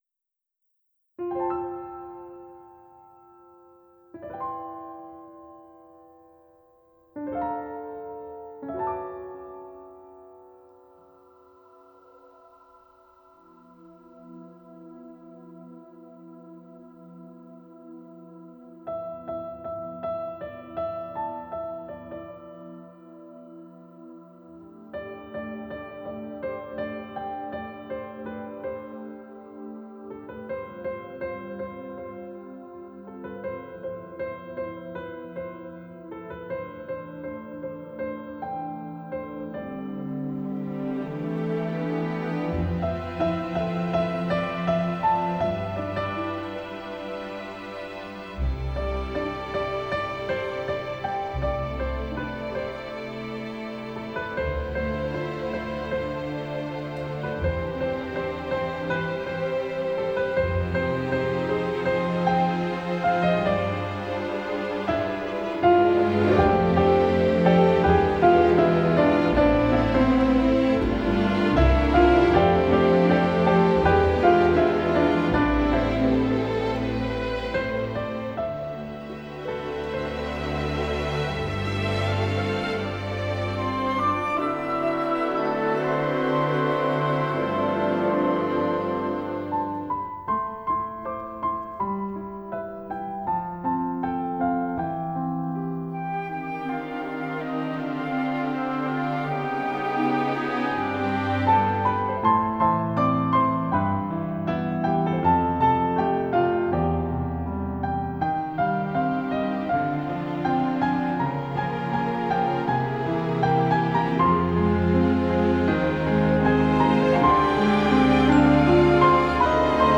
fail-sound.wav